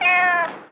Cat.wav